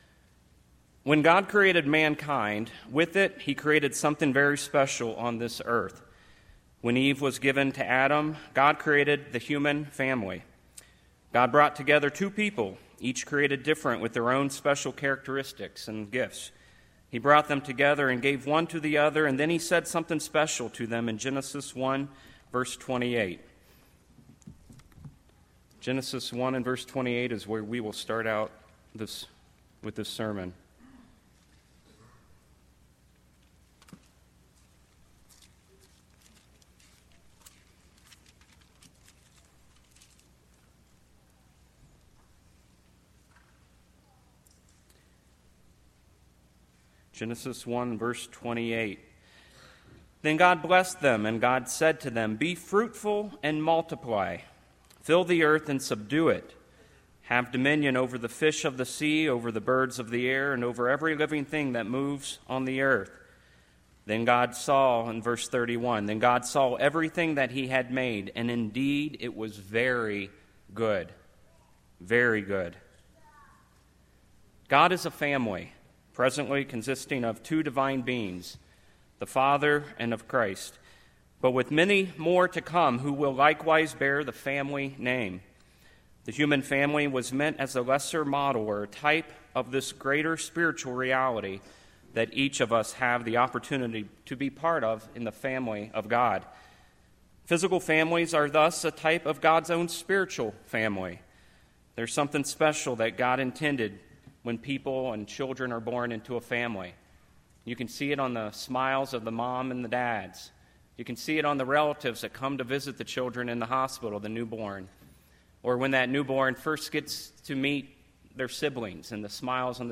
This sermon was given at the Snowshoe, West Virginia 2018 Feast site.